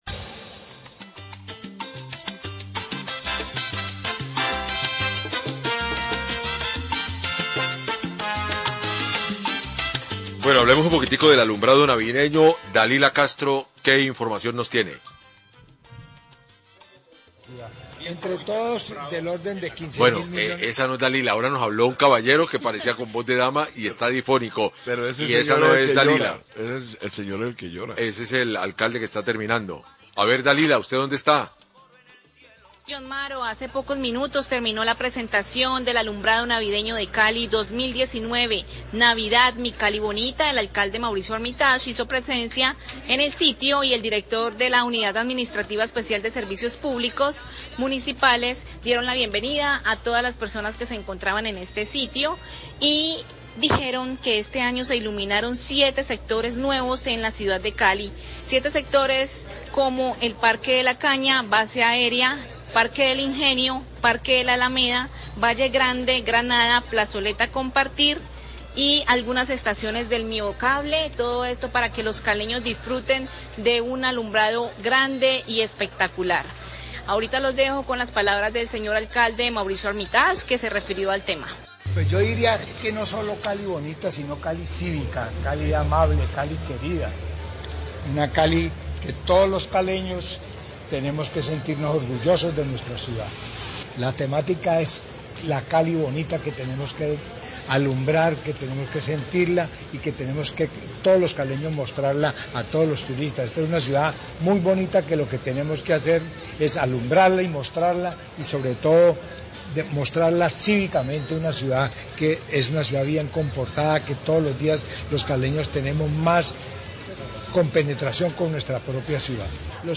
ALCALDE DE CALI SE REFIRIÓ A LA PRESENTACIÓN DEL ALUMBRADO NAVIDEÑO, RADIO CALIDAD, 646am
NOTICIAS DE CALIDAD